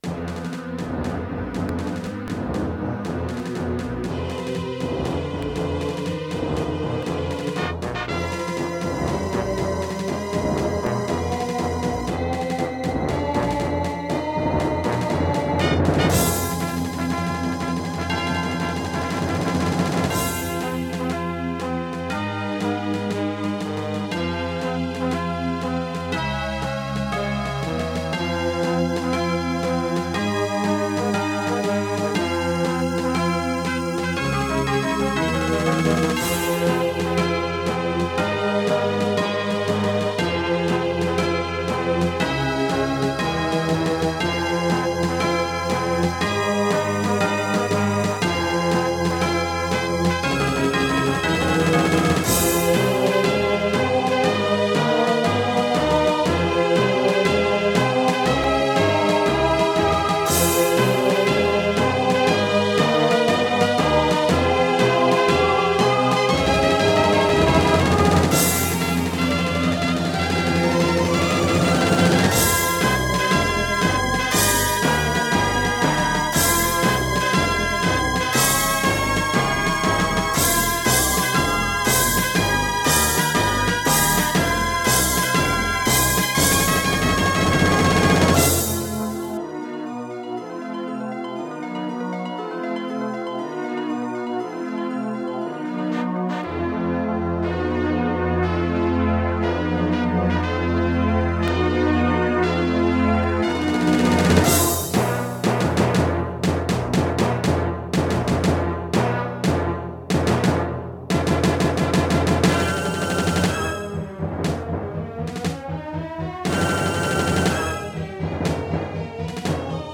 Terratec WaveSystem SIWT-1
* Some records contain clicks.